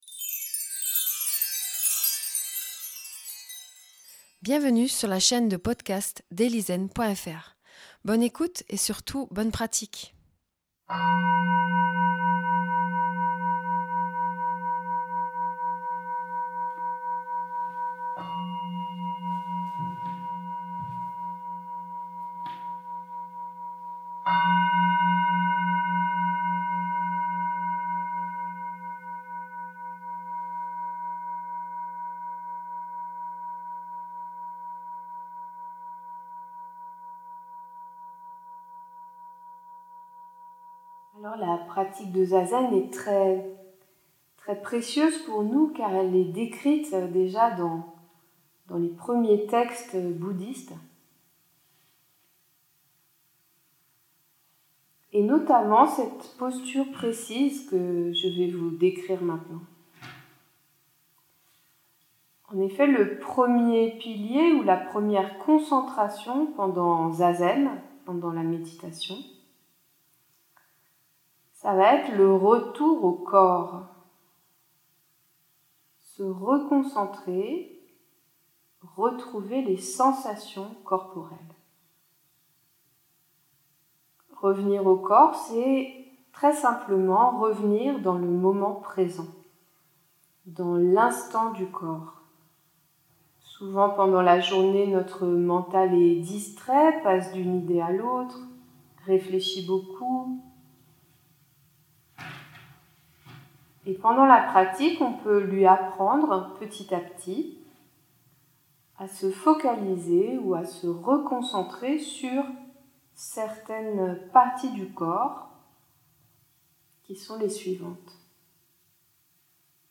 Méditation-guidée.mp3